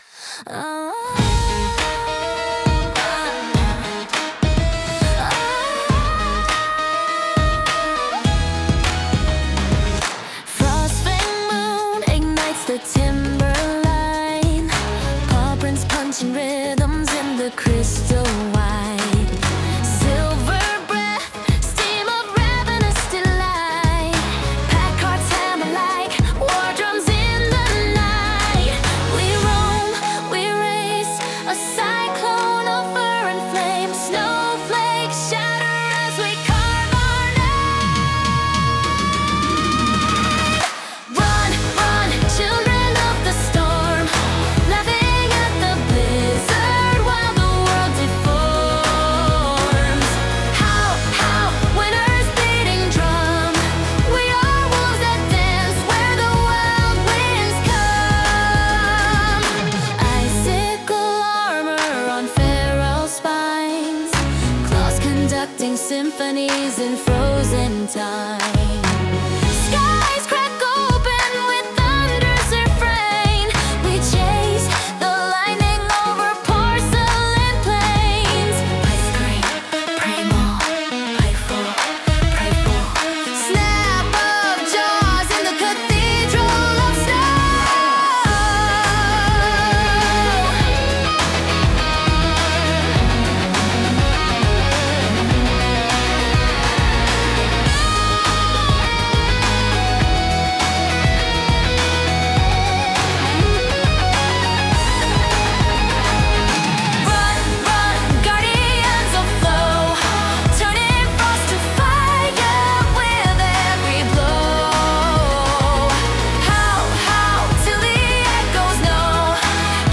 Some lyrics I’ve been playing around with for a while, put to music with Suno AI: